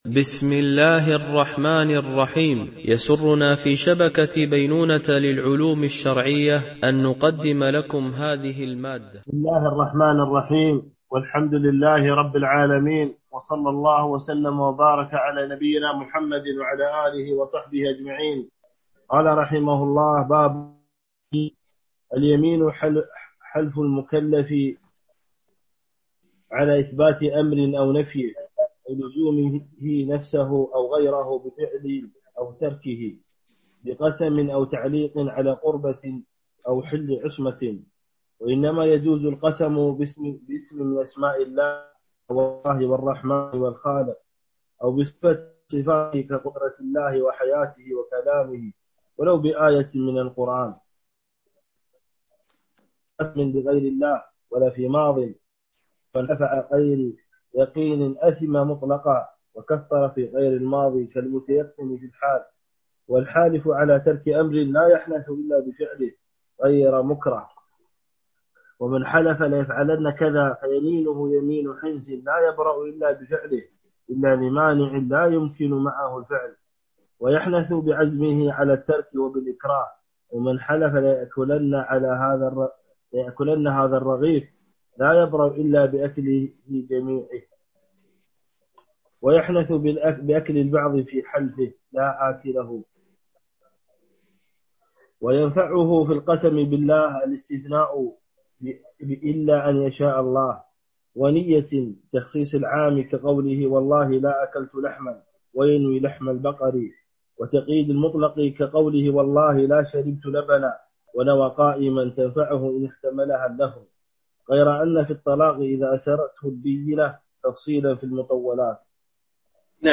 شرح الفقه المالكي ( تدريب السالك إلى أقرب المسالك) - الدرس 33 ( كتاب الأضحية والعقيقة )